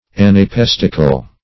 Anapestical \An`a*pes"tic*al\, a.